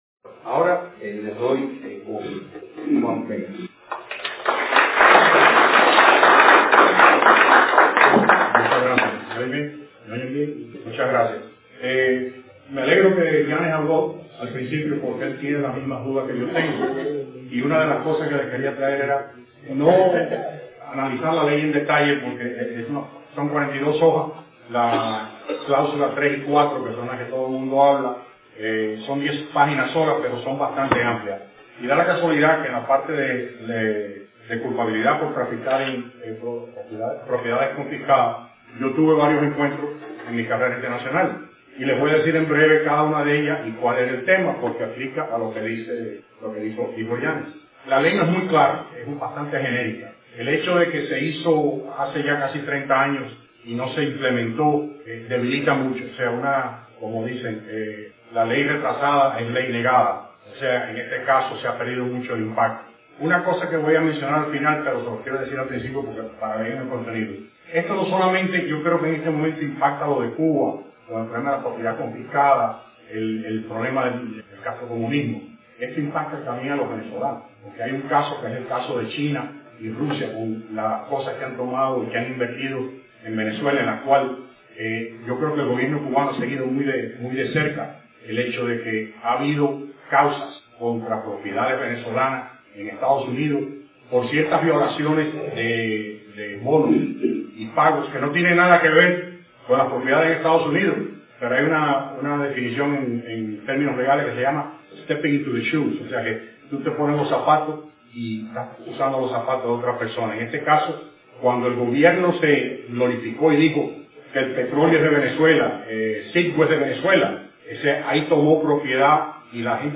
Audios de programas televisivos, radiales y conferencias